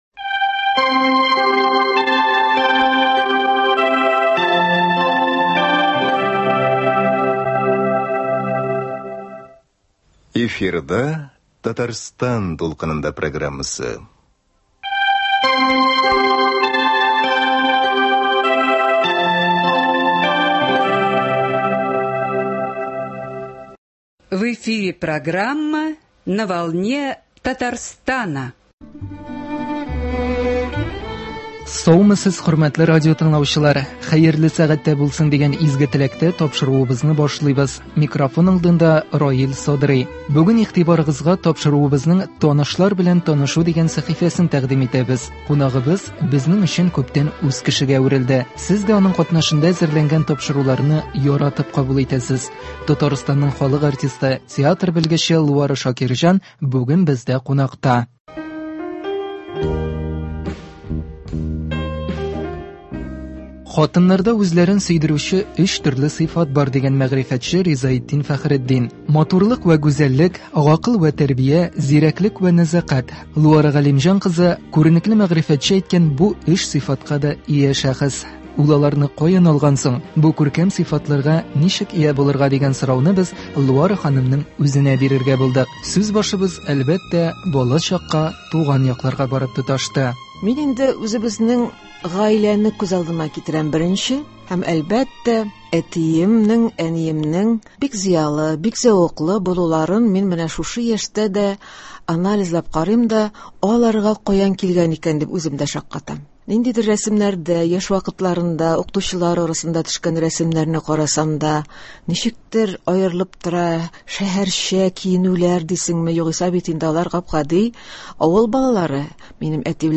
Әңгәмә барышында аның үзен дә иҗади шәхес итеп ачуга ирештек.